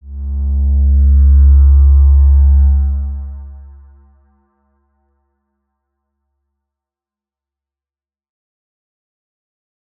X_Windwistle-C#1-ff.wav